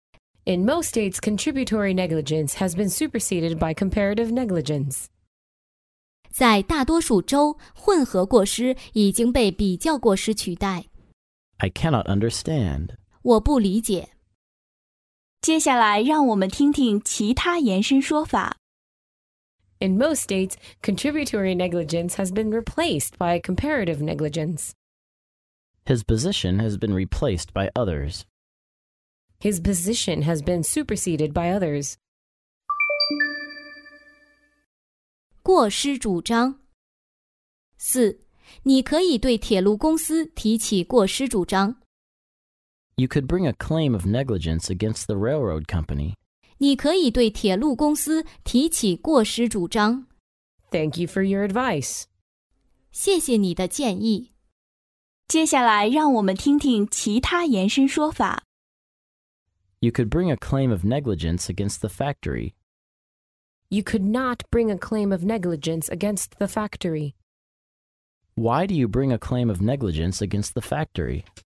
在线英语听力室法律英语就该这么说 第145期:在大多数州混合过失已经被比较过失取代的听力文件下载,《法律英语就该这么说》栏目收录各种特定情境中的常用法律英语。真人发音的朗读版帮助网友熟读熟记，在工作中举一反三，游刃有余。